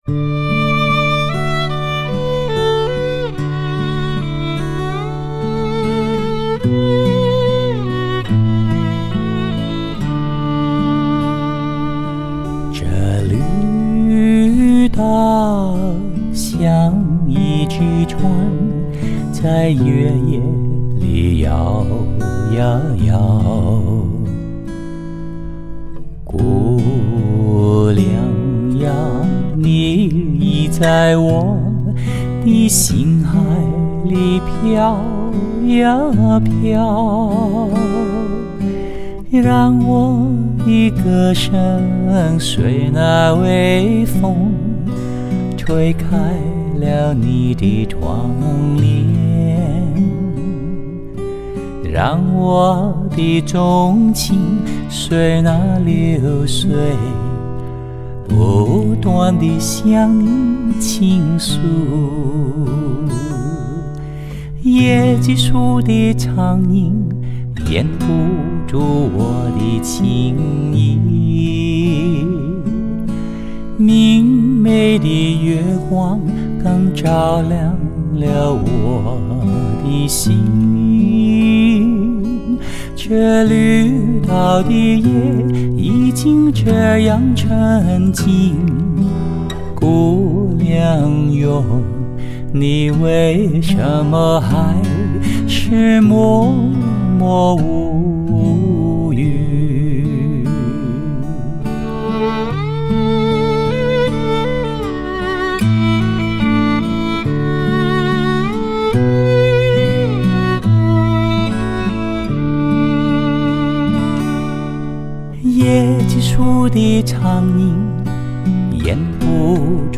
香港首席发烧男声
深沉的嗓音带给你浓郁的民歌情感！
顶级的录音器材及技术，结合顶级歌手的靓声和唱功，都是所有音乐制作人期望见证、参与的终极梦想。